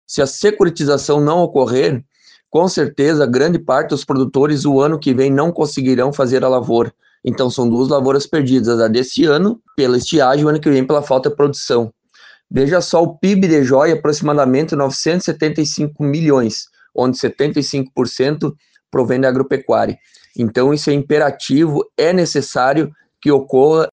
Segundo o prefeito, Dionei Lewandowski, se não houver securitização, muitos agricultores paralisarão atividades. Confira, abaixo, áudio do prefeito Lewandowski.